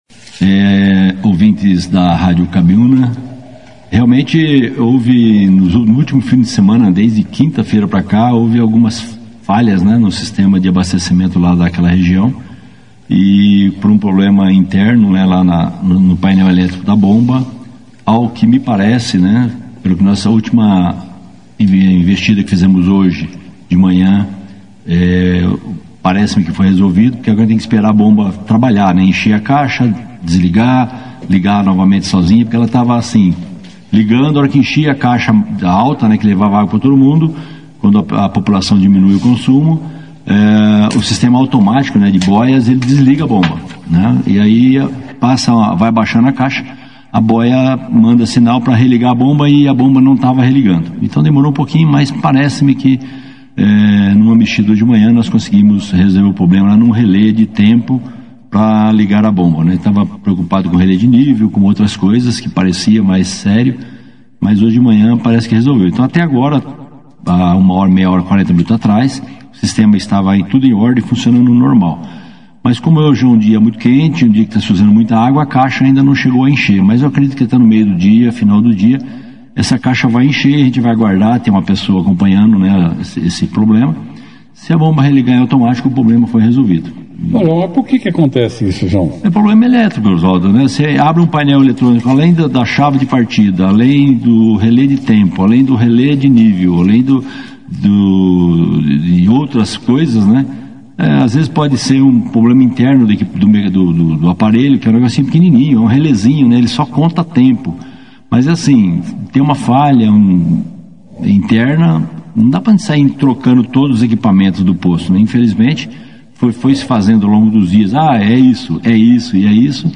O diretor do Serviço Autônomo de Água e Esgoto de Bandeirantes, Joao Guin , (foto), participou da 2ª edição do Jornal Operação Cidade nesta quarta-feira, 3 de dezembro de 2025. João falou sobre a falta de água que vem afetando principalmente os moradores do Jardim San Rafael desde o último domingo, situação que tem gerado preocupação na região.
Durante a entrevista, ele também comentou sobre o concurso Público para provimento de vagas efetivas e formação de cadastro de reserva do seu quadro de pessoal que será realizado neste domingo 07 de dezembro de 2025, no período da manhã, no Campus da UENP, situado na Rodovia BR 369, Km 54, Vila Maria (Saída para Andirá) em Bandeirantes – Paraná.